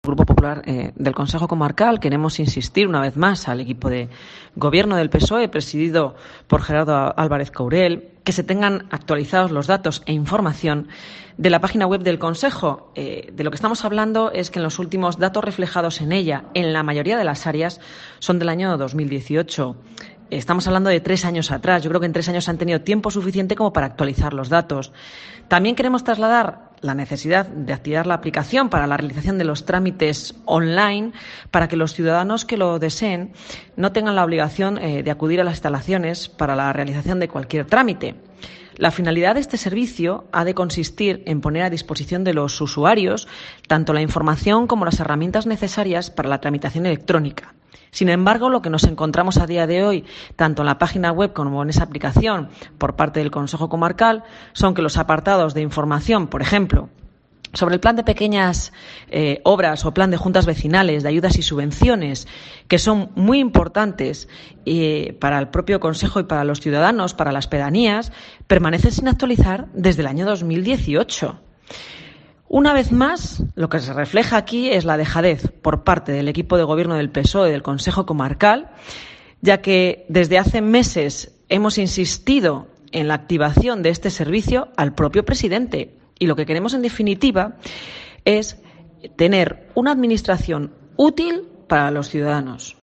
AUDIO: Escucha aquí a Rosa Luna, portavoz popular en el Consejo Comarcal del Bierzo